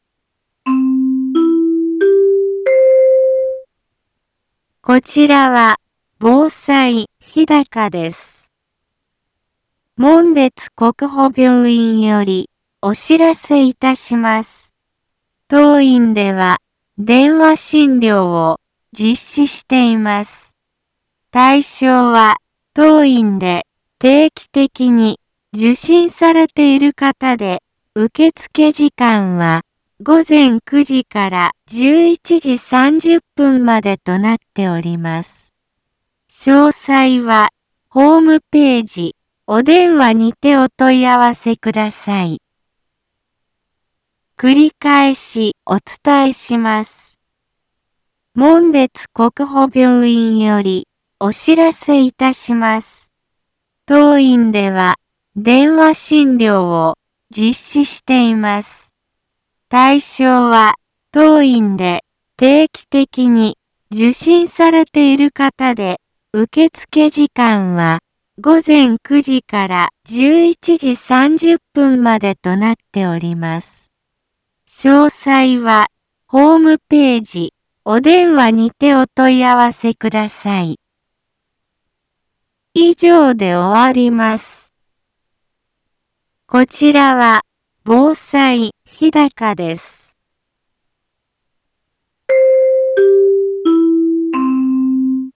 Back Home 一般放送情報 音声放送 再生 一般放送情報 登録日時：2020-05-07 15:03:23 タイトル：門別国保病院より電話診療のお知らせ インフォメーション：こちらは、防災日高です。